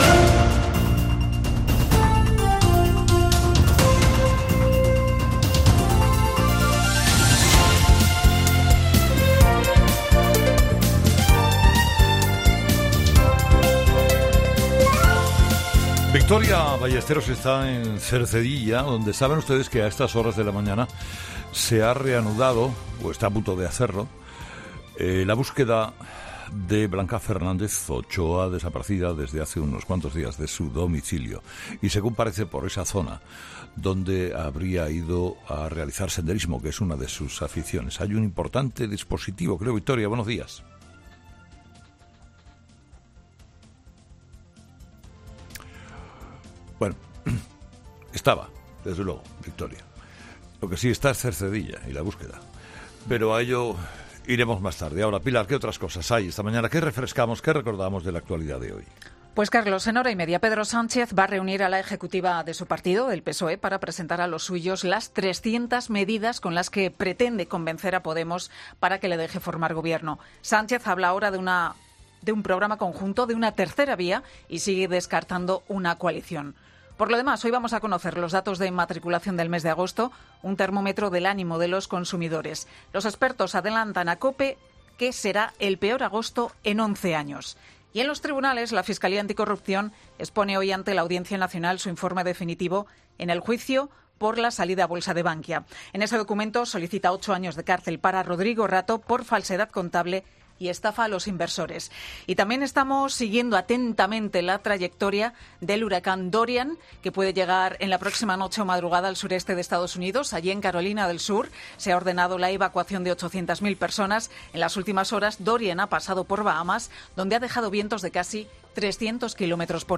Boletín de noticias COPE del 2 de septiembre a las 09.00